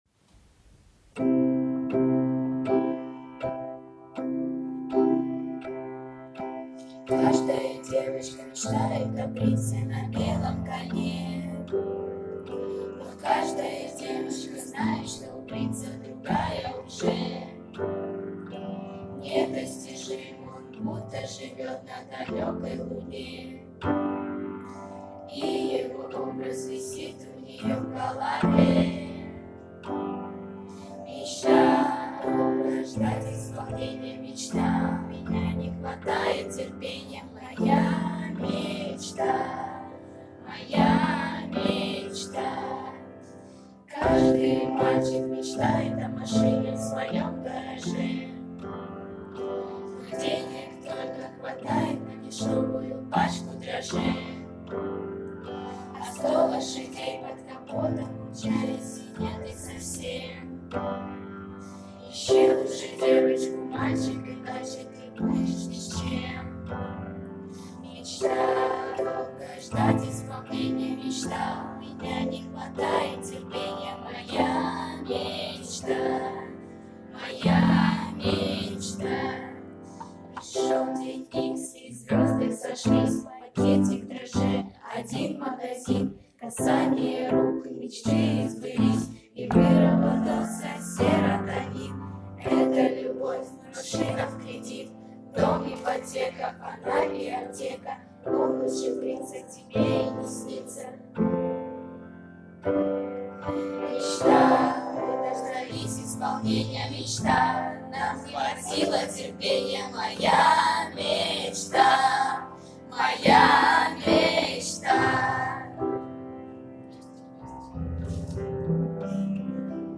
Мы писали песни на заданные темы и представляли их остальным отрядам.